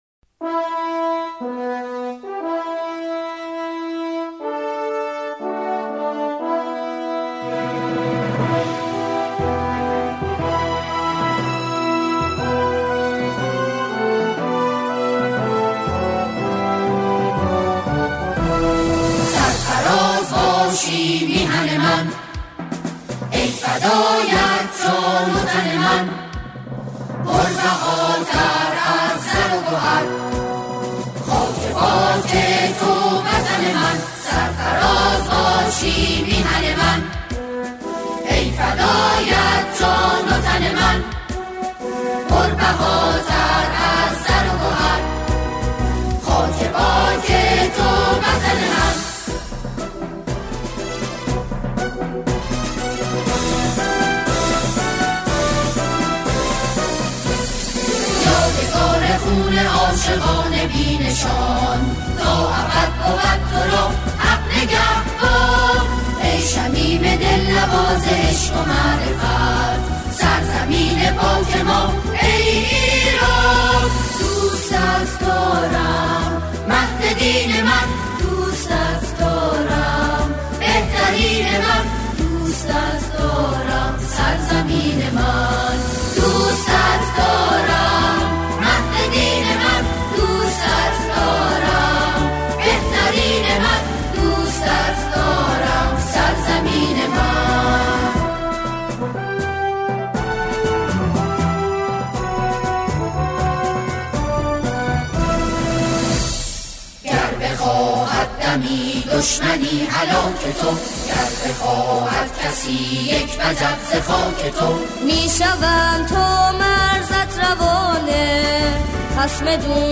آهنگ حماسی